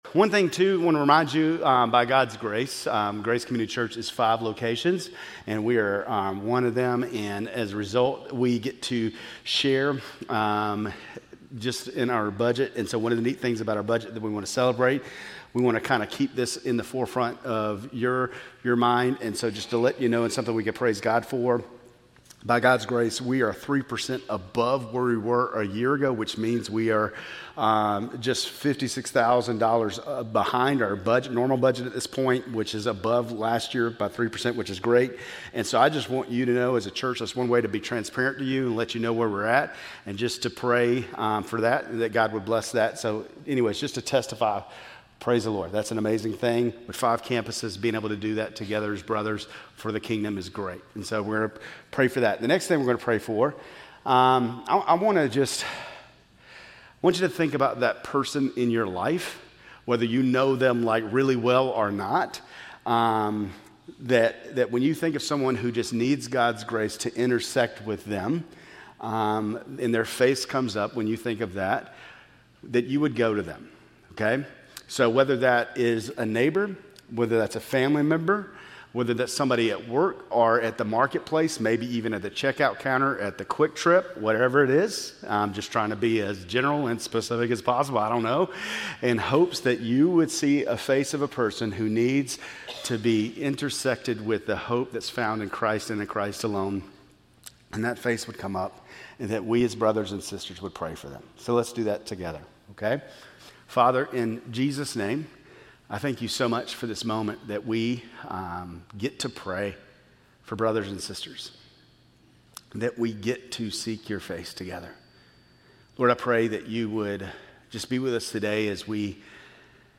Grace Community Church Lindale Campus Sermons 11_2 Lindale Campus Nov 03 2025 | 00:33:20 1x 00:00 / 00:33:20 Subscribe Share RSS Feed Share Link Embed